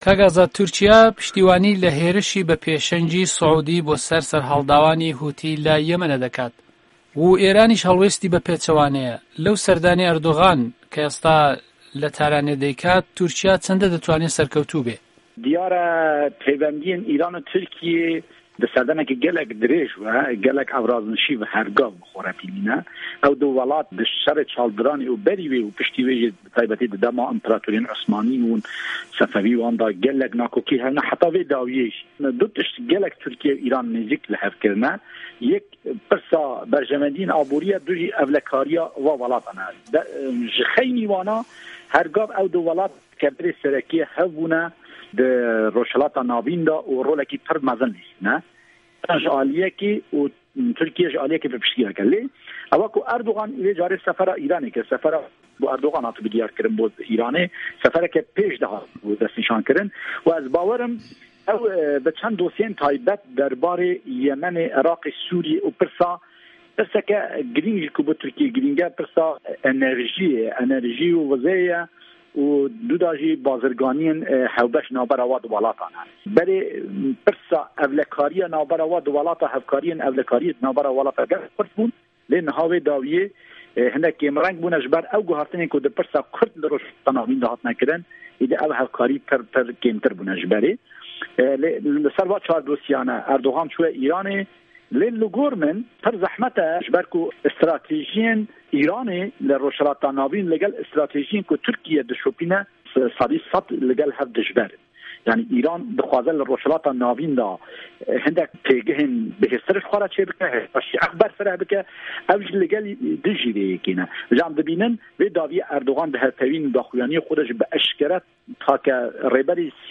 وتووێژی